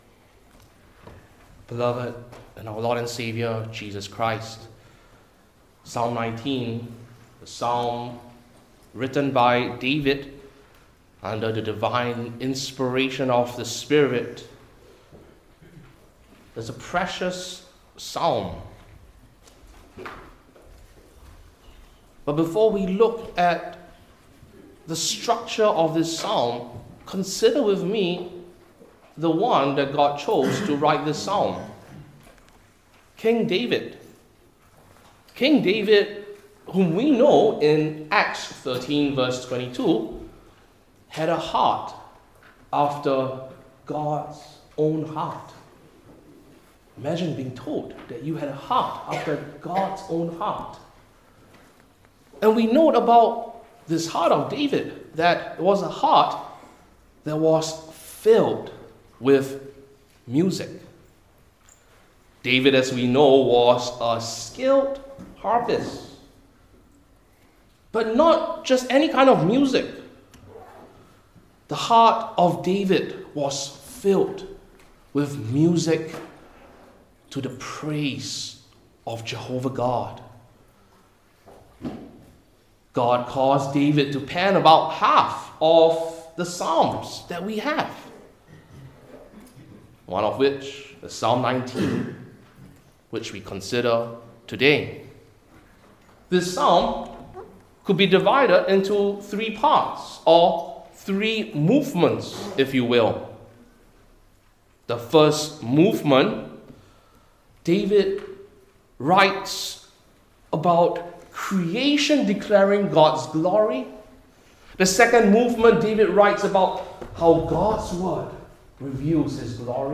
Old Testament Individual Sermons I. The Meaning II.